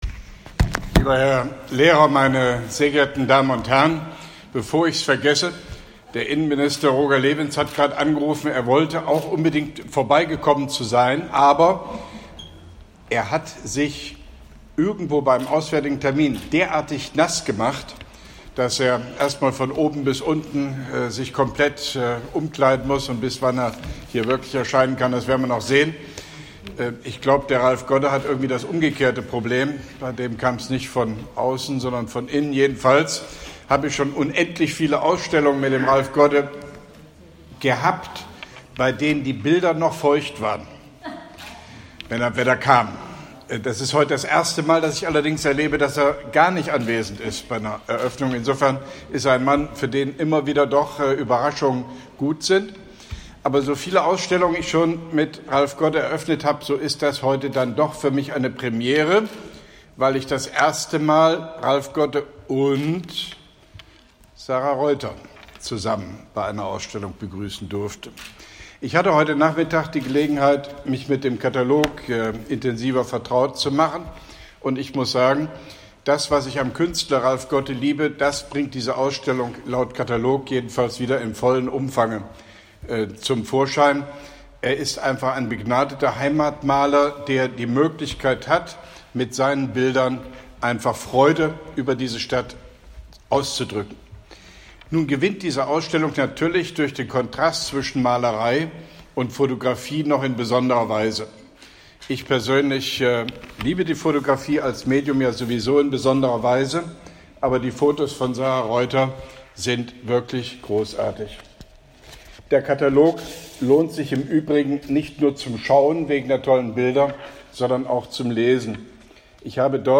Grußwort (hier als Schirmherr) von OB Hofmann-Göttig im Rahmen der 17. Langen Nacht der Museen 2017